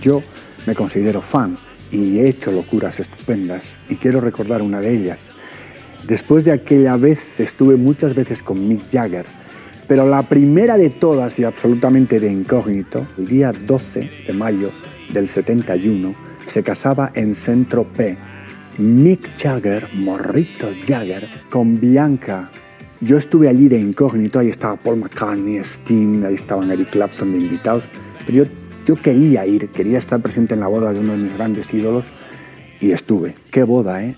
Comentari de Joaquín Luqui sobre el cantant Mick Jagger.
Musical